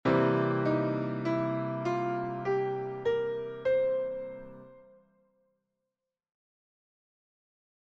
Blues Piano
Ajoutons une 3ce3^{ce} majeure à notre gamme pentatonique mineure.
tierce-majeure.mp3